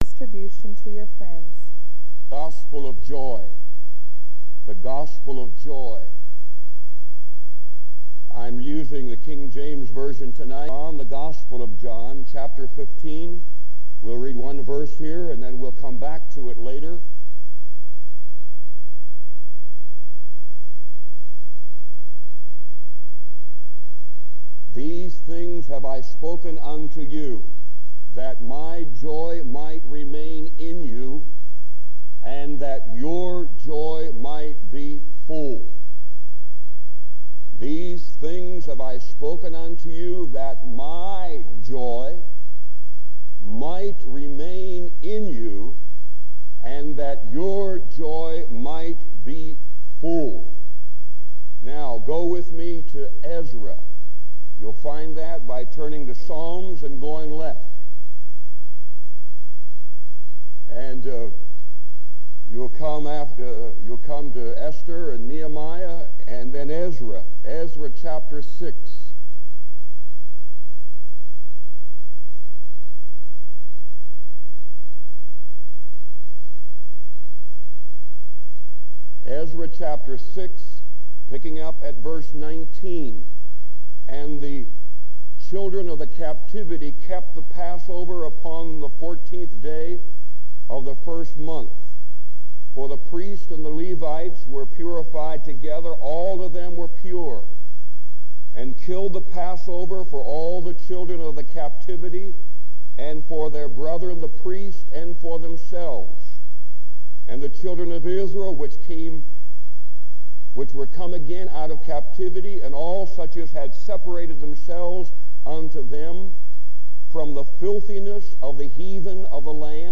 This sermon challenges listeners to embrace joy as their strength and live a Spirit-filled life.